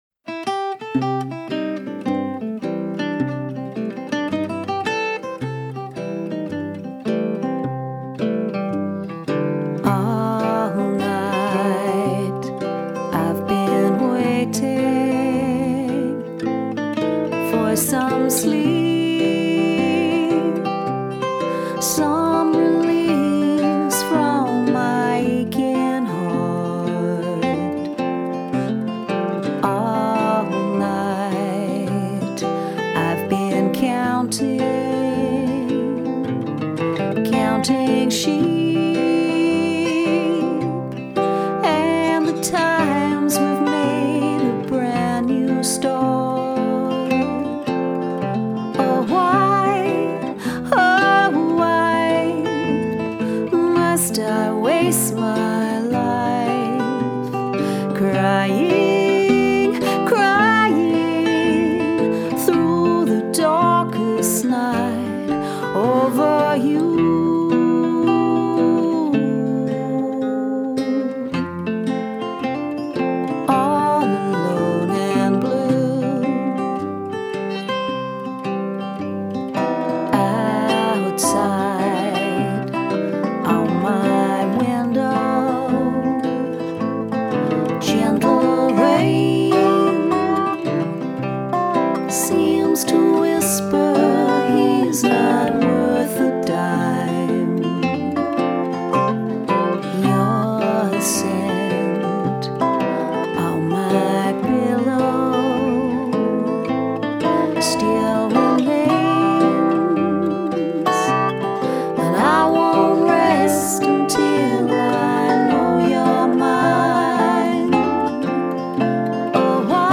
An intimate affair of no nonsense or frills
Vocals
Guitars, Timple, Banjo and Dobro
Double Bass